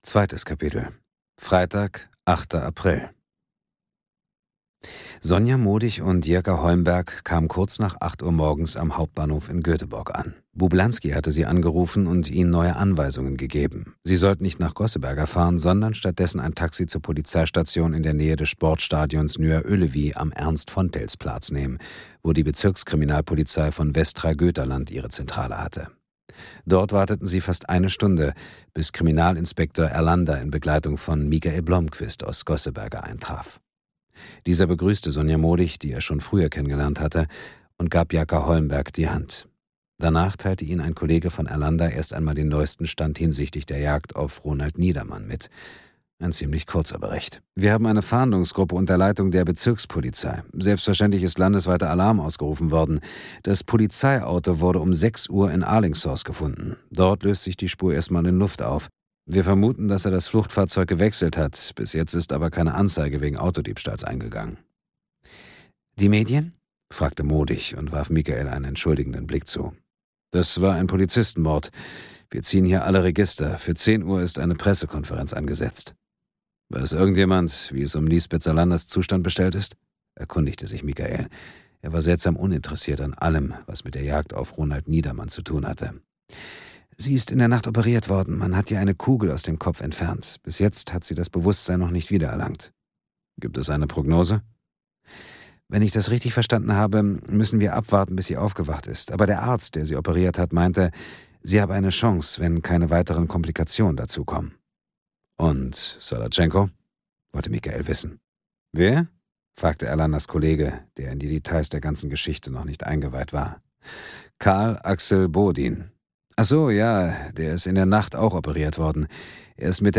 Vergebung audiokniha
Ukázka z knihy
• InterpretDietmar Wunder